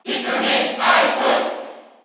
Category: Crowd cheers (SSBU) You cannot overwrite this file.
Alph_Cheer_Korean_SSBU.ogg.mp3